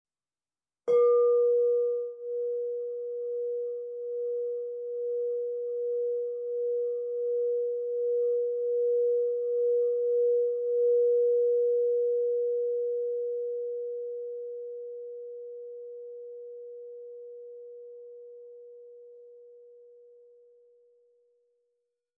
Meinl Sonic Energy 10" Solfeggio Crystal Singing Bowl 963 Hz (SOLCSB10-963)